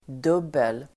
Uttal: [d'ub:el]